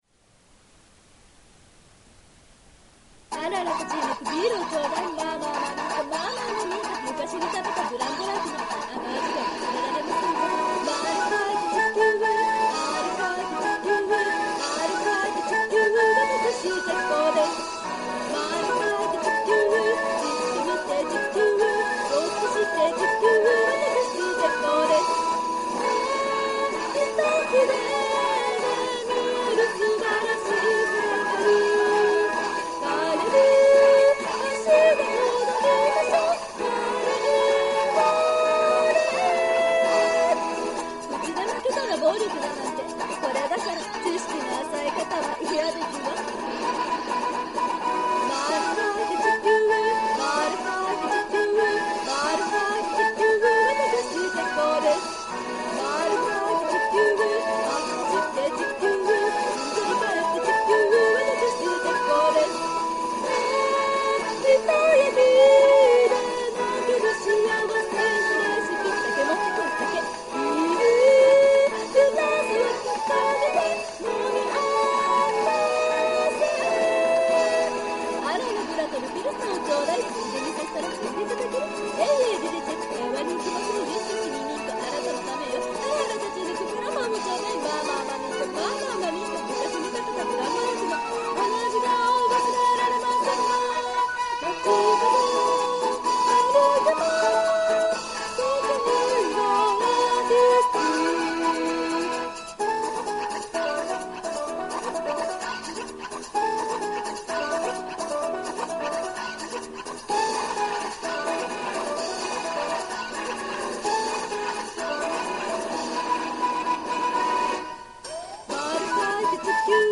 チェコの方は正直、納得いってません
本当はもっとどっしりした声のイメージなんです…